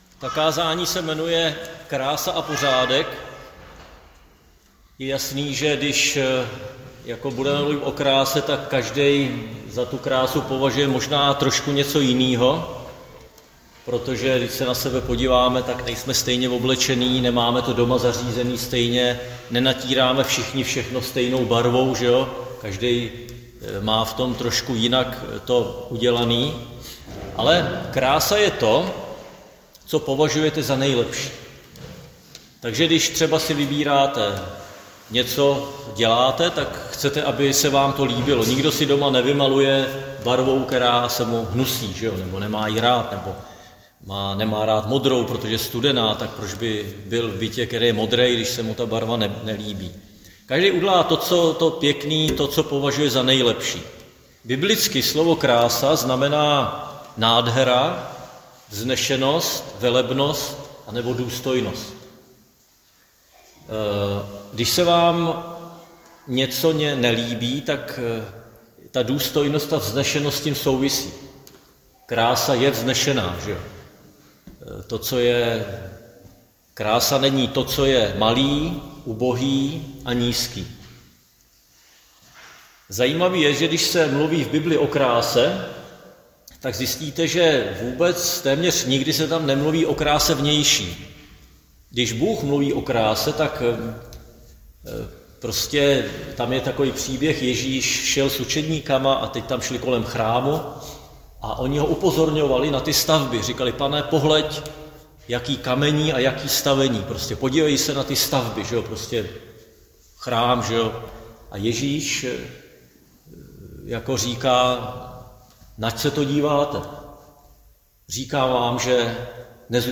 Křesťanské společenství Jičín - Kázání 6.4.2025